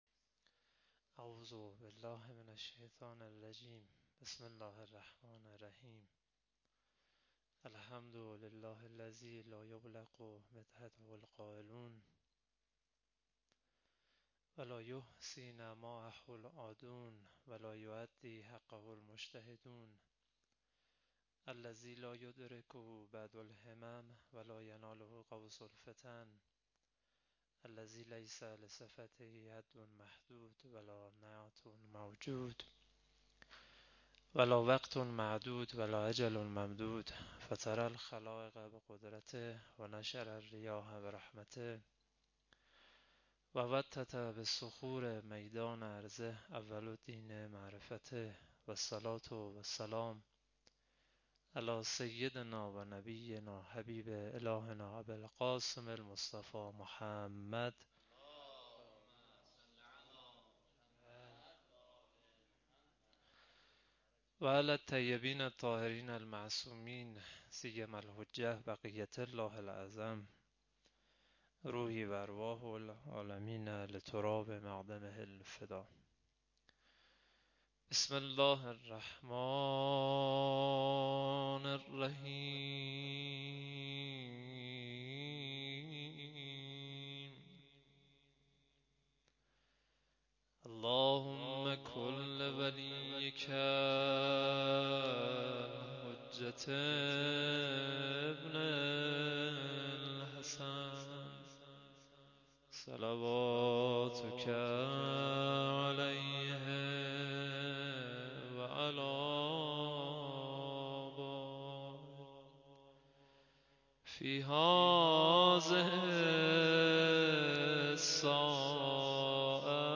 01-shahadat-emam-sadegh-sokhanrani.mp3